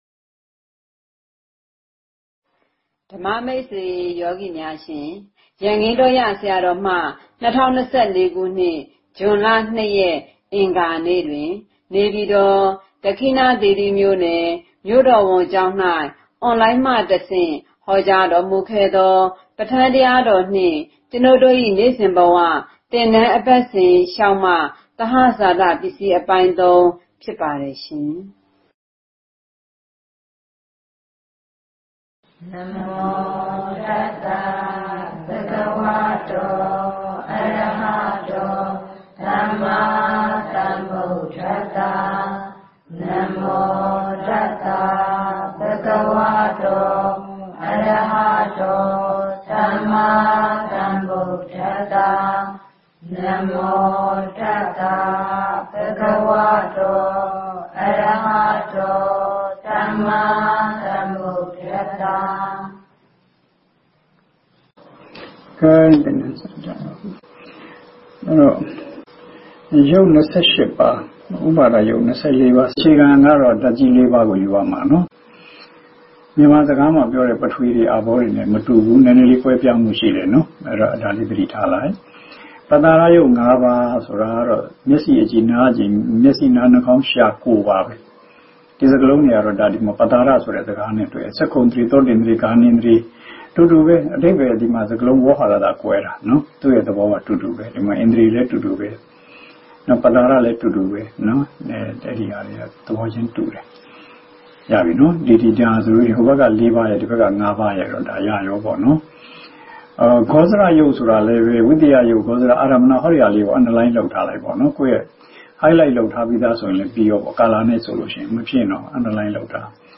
Audio Book ပဋ္ဌာန်းတရားတော်နှင့် ကျွန်ုပ်တို့၏နေ့စဉ်ဘဝသင်တန်း (အပတ်စဉ် ၆) ၆။ သဟဇာတပစ္စည်း January 29, 2025 · Dhamma Training Center အောက်ပါ link မှ download ရယူနိုင်ပါသည် 001- 6.